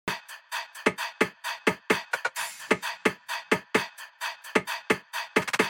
DS_BPG_130_drum_top_glam